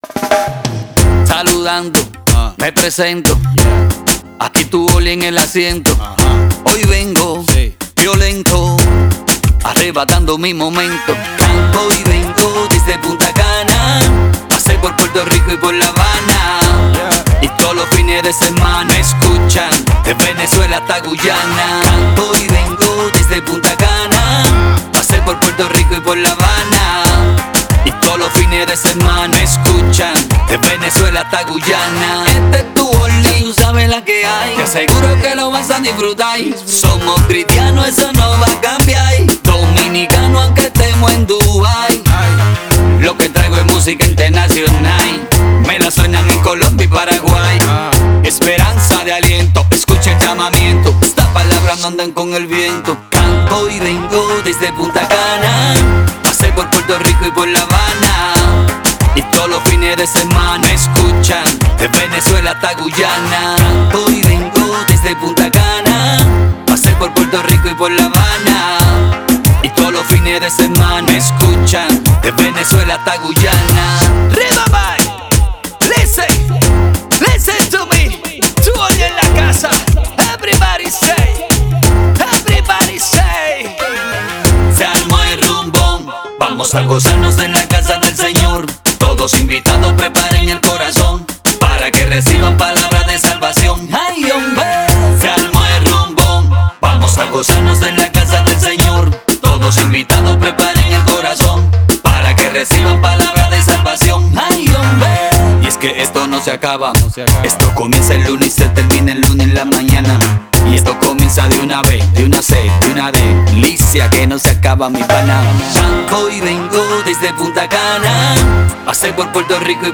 это зажигательная композиция в жанре реггетон